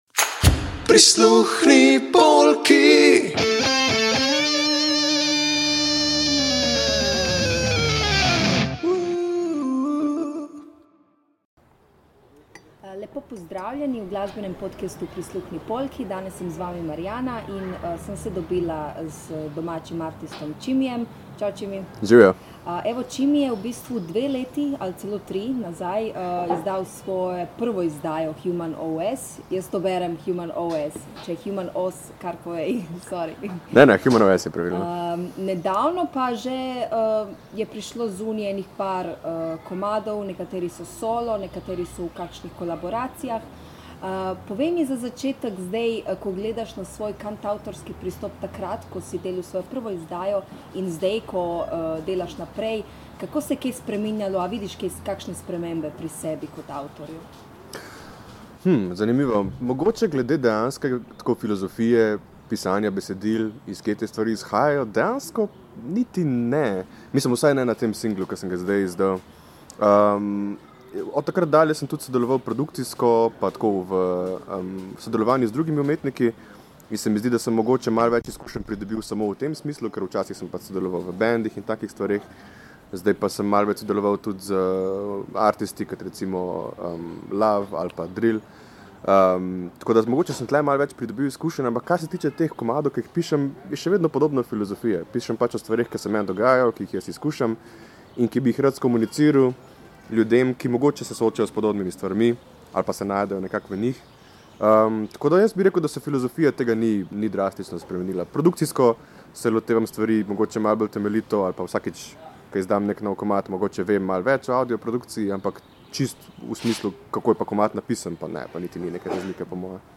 Več o tem v intervjuju.